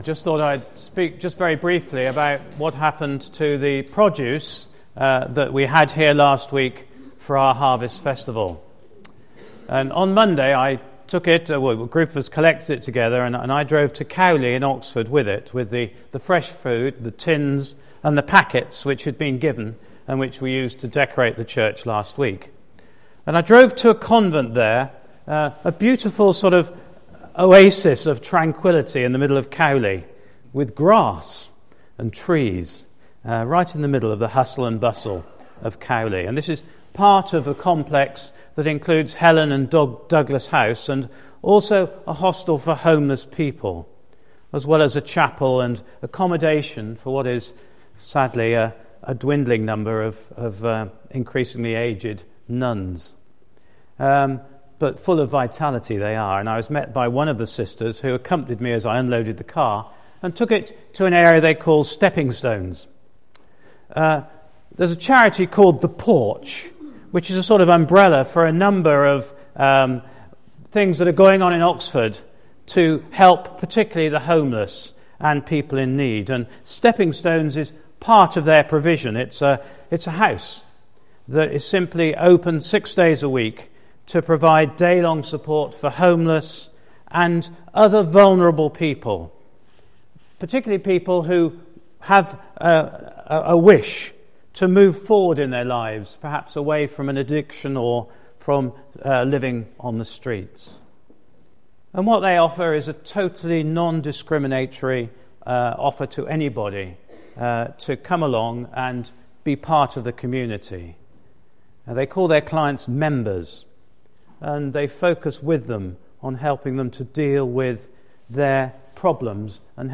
Today’s service celebrates Back to Church Sunday and there are four talks.
Talk-1-The-Porch.mp3